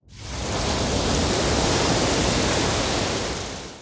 Wave Attack 2.wav